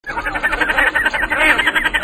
La Sarcelle d'hiver et la Sarcelle d'été